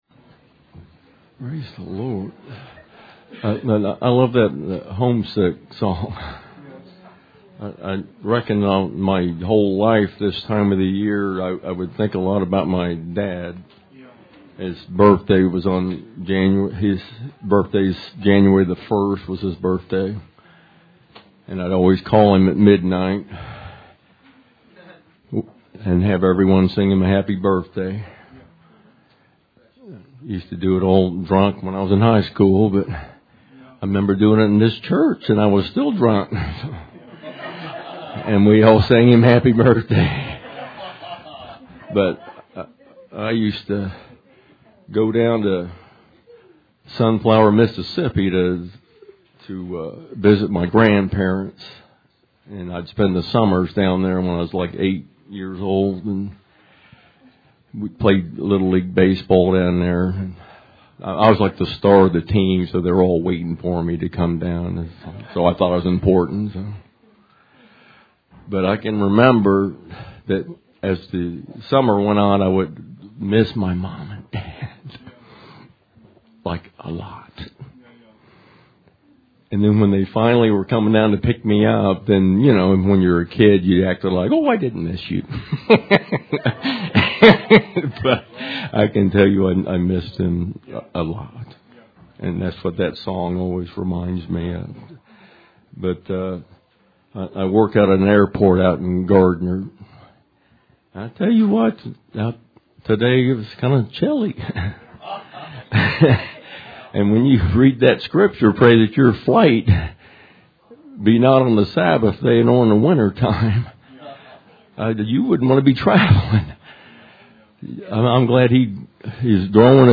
Sermon12.30.17